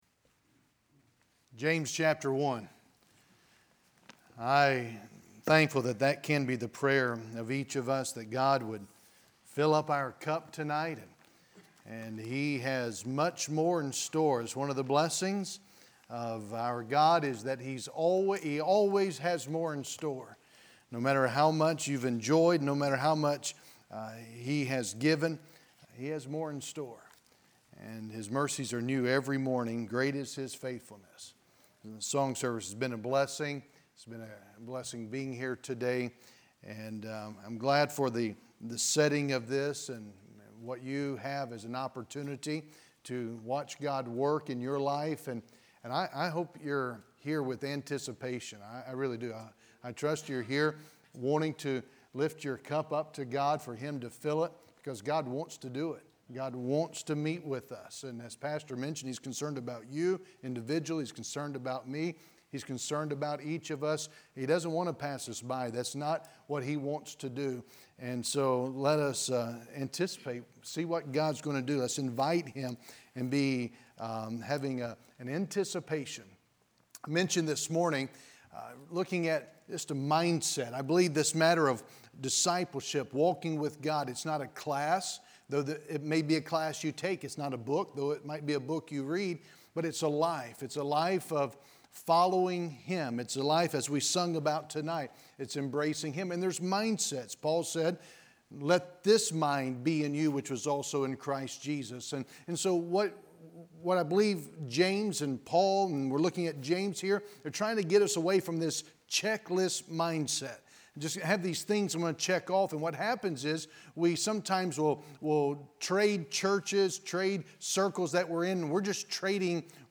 Preached on Wednesday, January 12, 2022